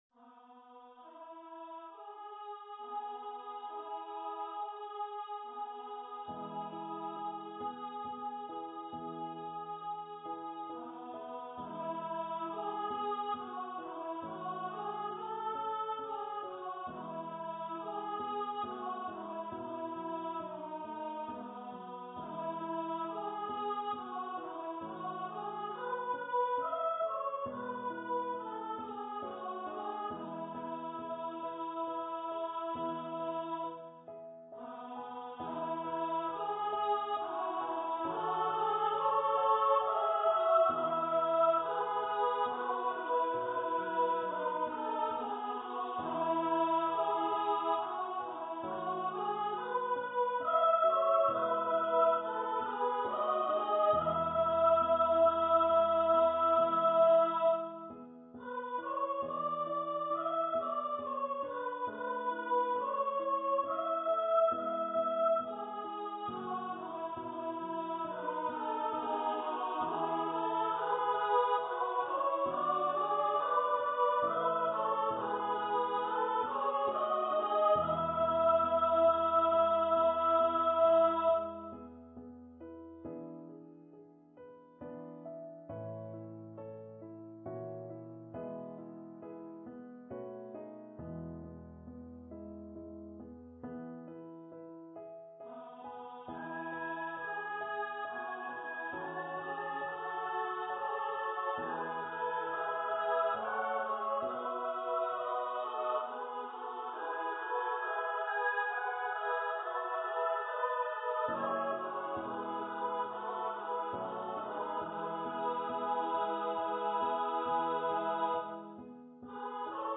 for female voice choir and piano
Scottish song arranged for SSA choir and piano.
Choir - 3 part upper voices